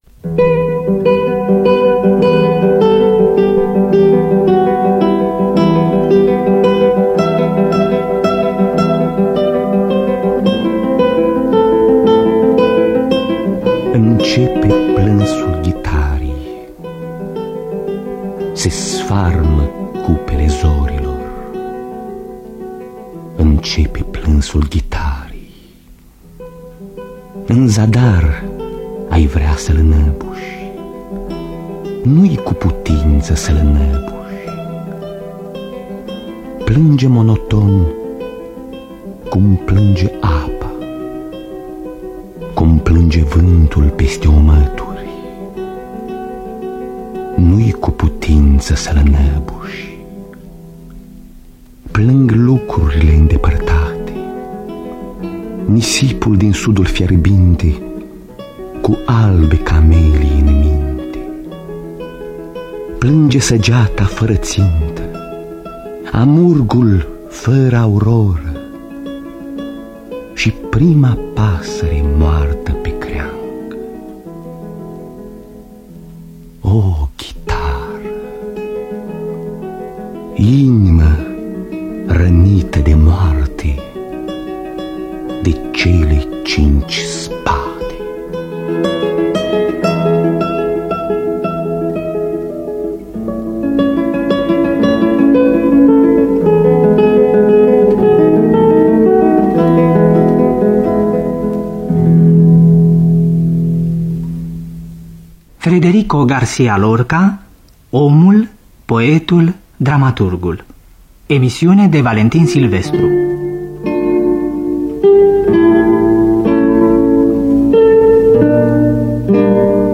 Conține fragmente din dramatizările radiofonice ale pieselor lui Federico Garcia Lorca.